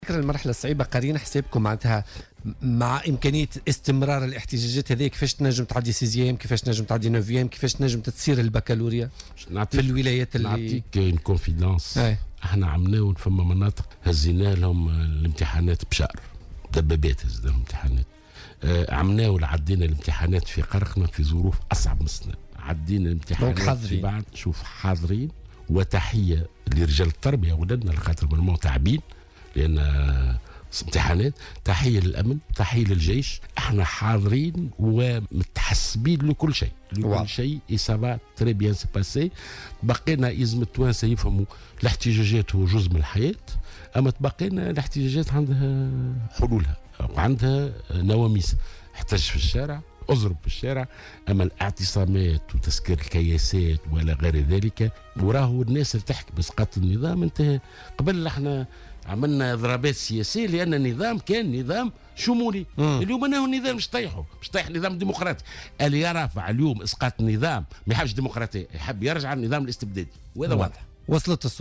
كشف وزير التربية اليوم الاثنين لـ "الجوهرة أف أم" انه تم نقل امتحانات البكالوريا العام الماضي على متن دبابات في بعض المناطق.